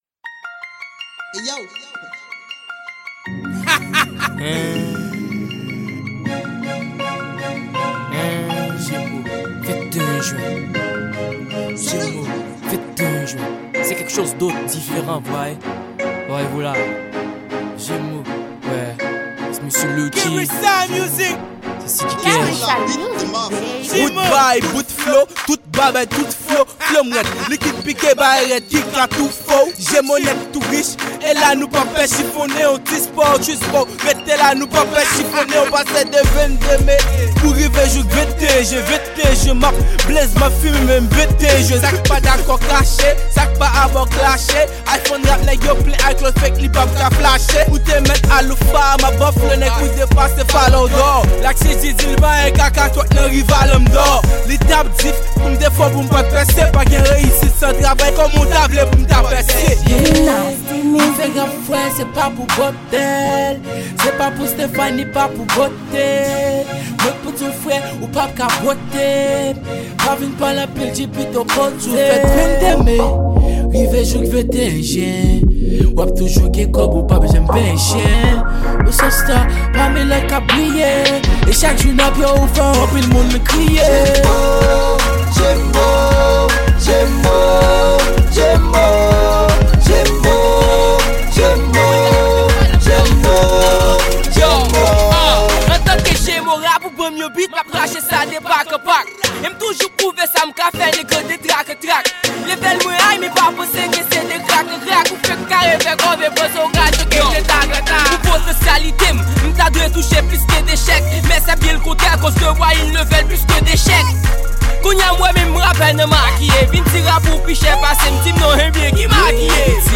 Genre: Rap & Rnb.